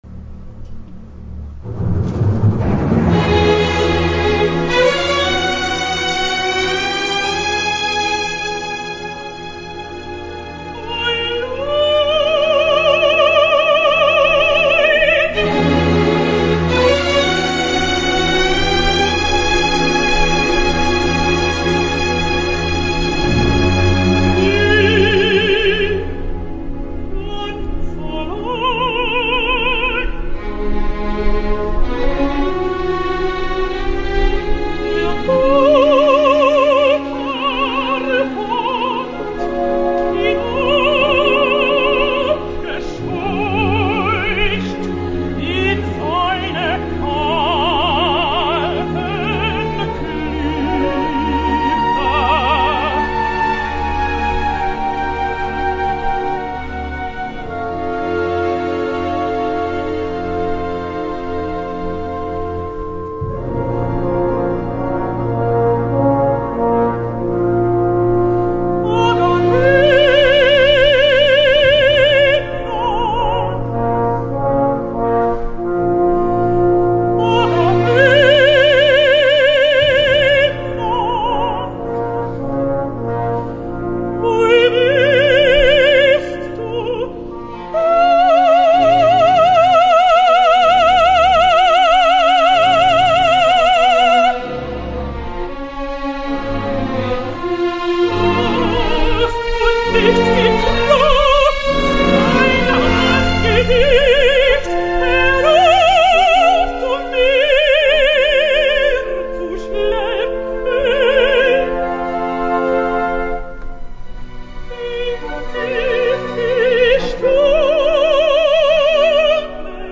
En el rol principal hi trobem a la soprano nord-americana Christine Goerke, que va debutar aquest rol fa un any al Teatro Real de Madrid, alternant-se amb Deborah Polaski.
És una veu en principi molt adequada a aquest rol sense concessions. El camí ascendent cap a les notes més agudes resulta a vegades menys impressionant, ja que la veu tendeix a perdre l’impressionant densitat que mostre en el registre central, però tot i així l’impacte està garantit.
Weh, ganz allein” amb una veu penetrant que talla com l’acer quan ataca els aguts, veritablement imposa molt.
Live from the Civic Opera House
Elektra: Christine Goerke
Conducted by Sir Andrew Davis